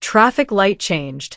traffic_sign_changed.wav